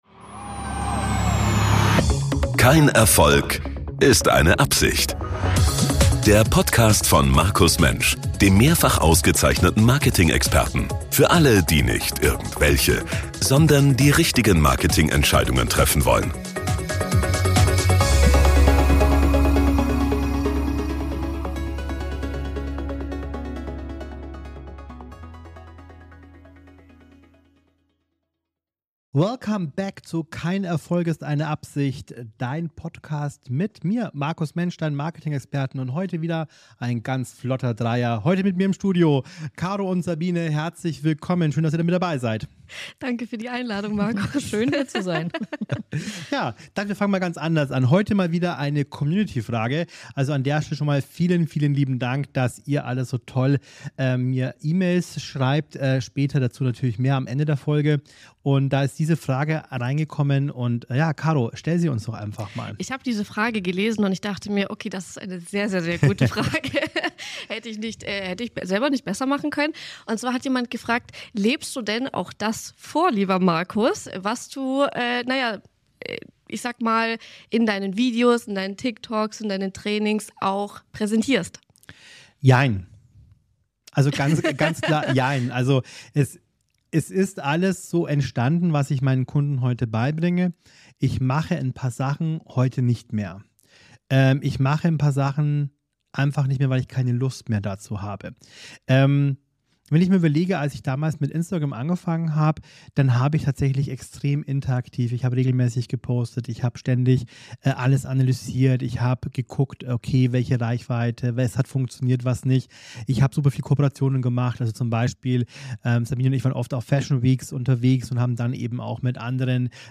Dieses Gespräch ist ein Muss für jeden, der sich für die Dynamik des Marketings, persönliche Markenentwicklung und realistische Ansätze zur Geschäftsführung interessiert.